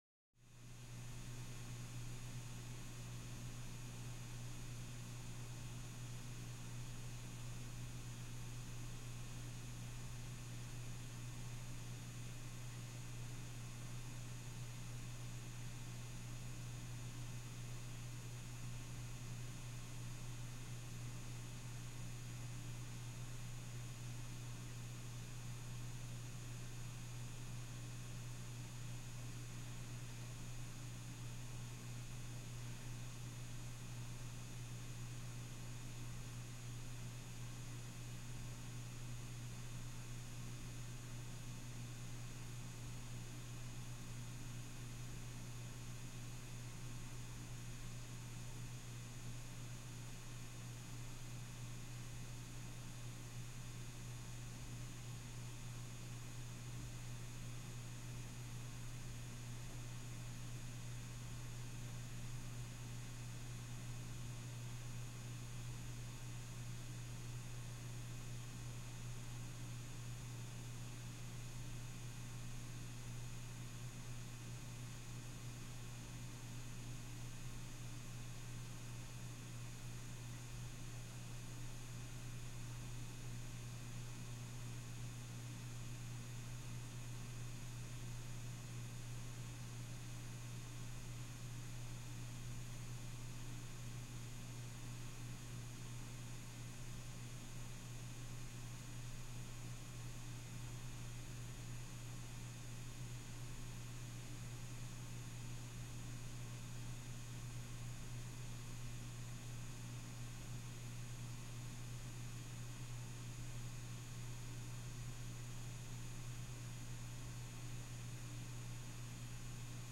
На этой странице собраны звуки библиотеки: тихий шелест страниц, шаги между стеллажами, отдаленные голоса читателей.
Шум компьютерного зала в библиотеке